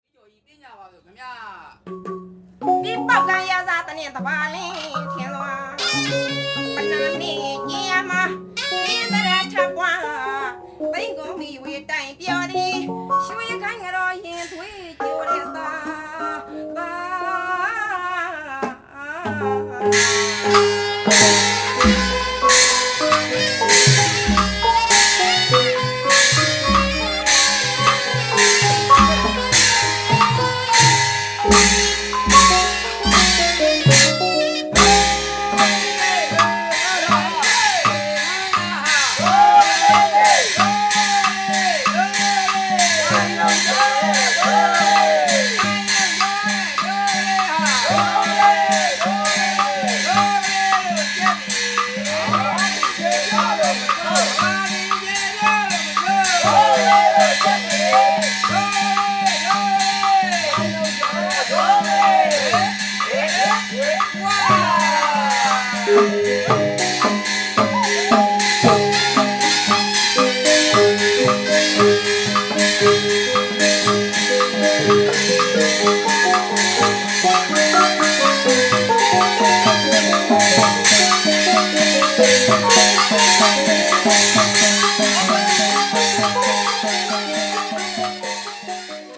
Nat Pwe ritual
Female singers perform songs from the nat than repertory, odes to the nats, taken from the Mahagita Medanikyan, a classical anthology.
The orchestra --often amplified-- accompanies the songs and the dances, with its deafening and trance-inducing sounds. The gong chime is the central instrument.